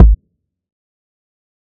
MD Kicks (1).wav